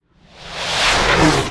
fx_explosion_artillery_near_01.wav